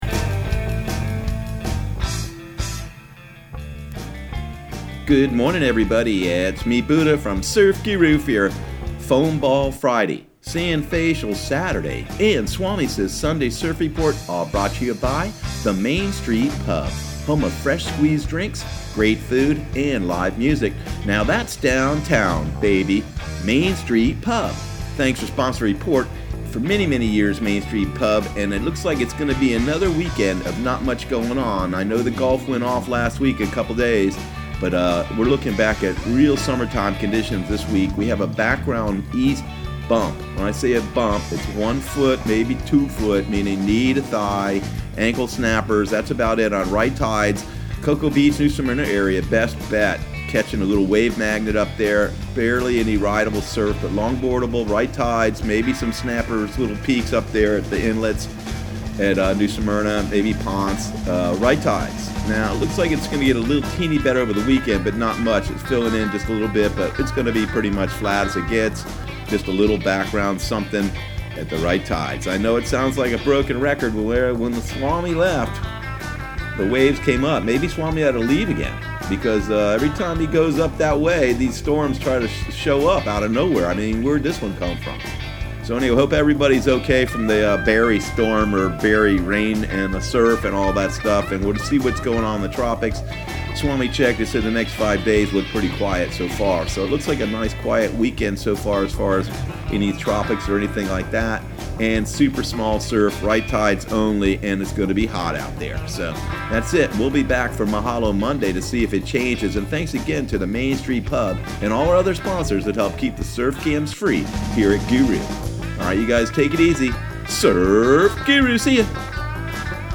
Surf Guru Surf Report and Forecast 07/19/2019 Audio surf report and surf forecast on July 19 for Central Florida and the Southeast.